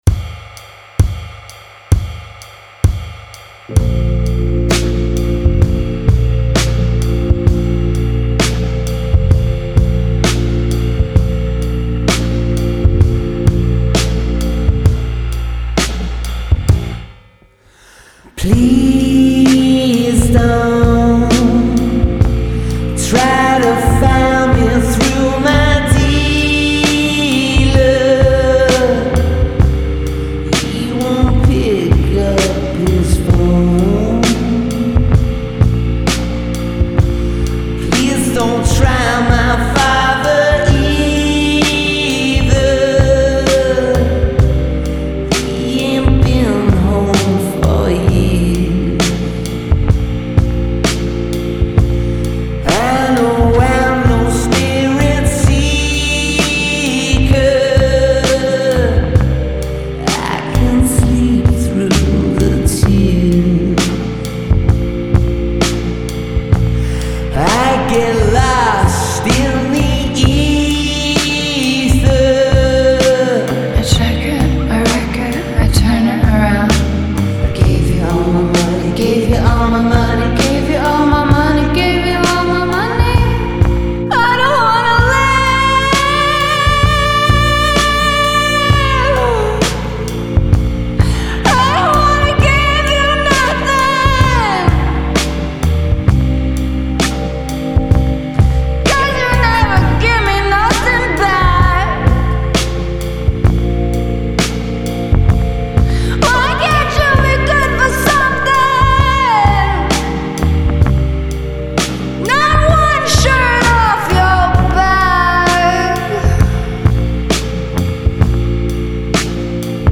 Genre : Alternative, Pop